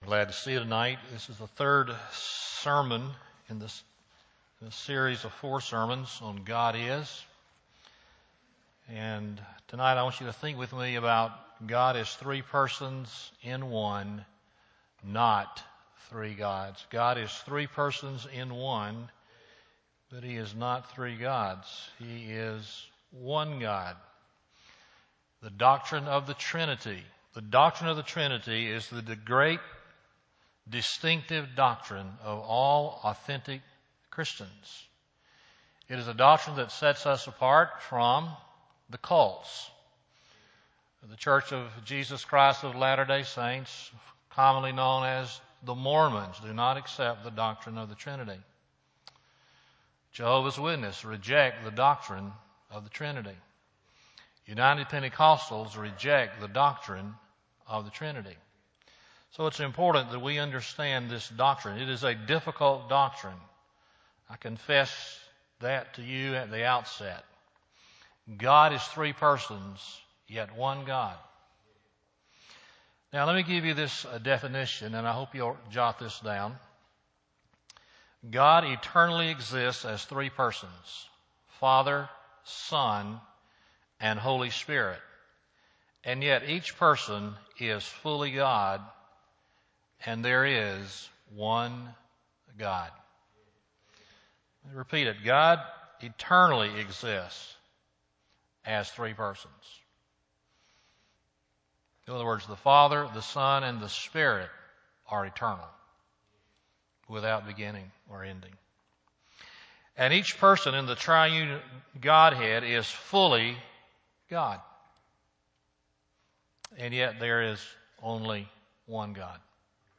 God is Three Persons, Not Three Gods Sermon Date: September 11, 2011 PM Scripture: Genesis 1:1, 26 Sermon Series: God Is .